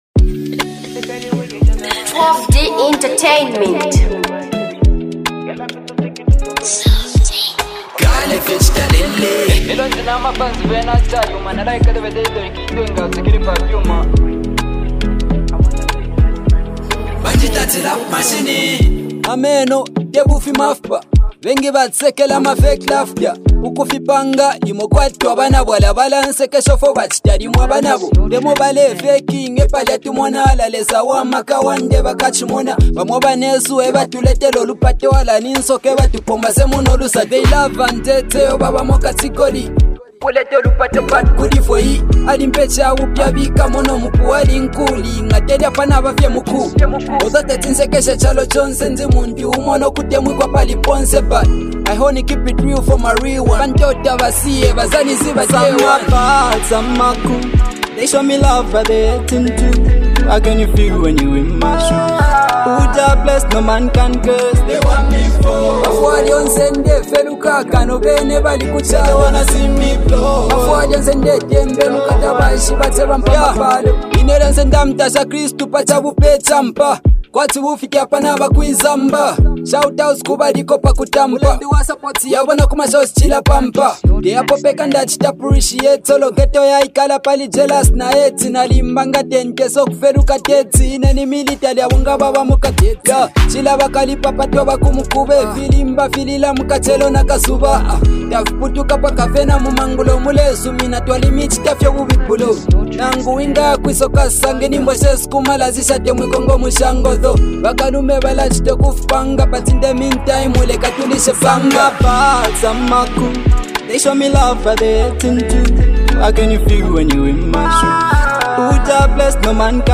heartfelt and emotional song